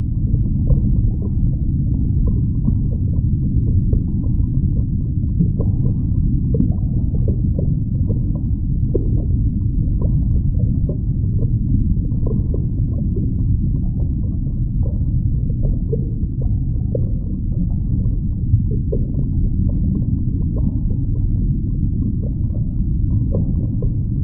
underwater_sea_diving_bubbles_loop_01.wav